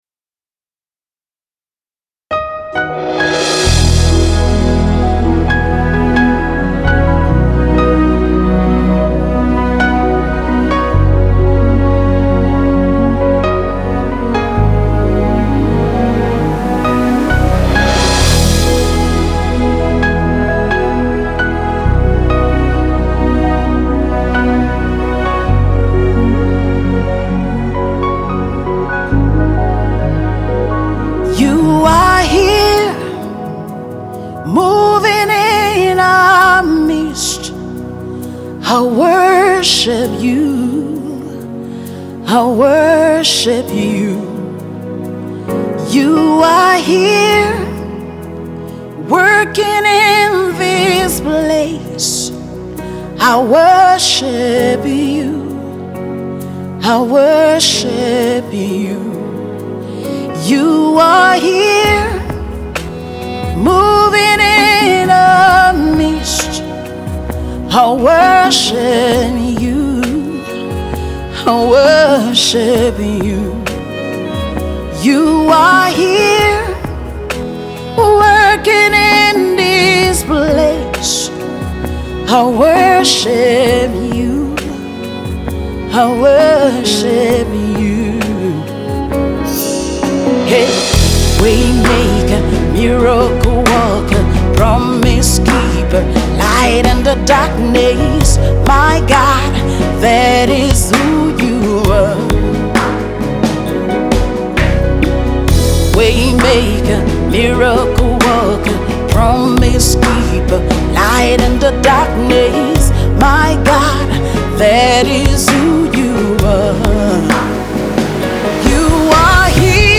spirit filled worship song